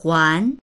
huán 4 返す
huan2.mp3